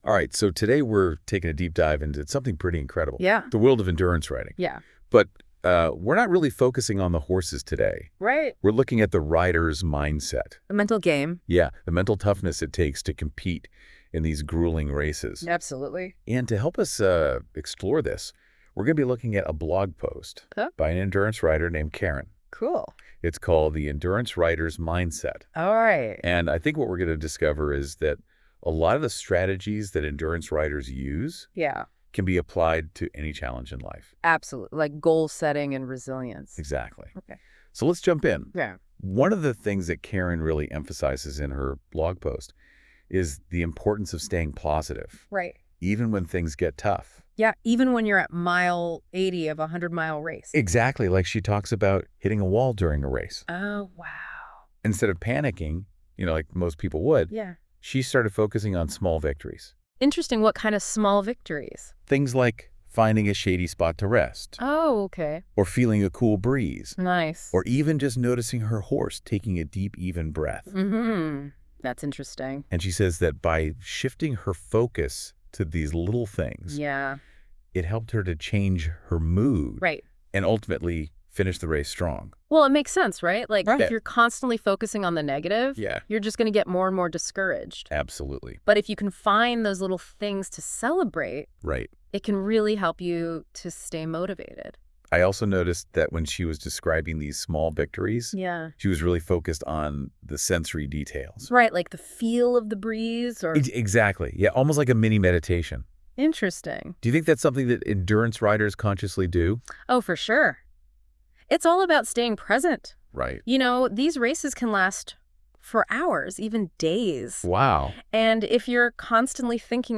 This is an AI generated overview of this topic: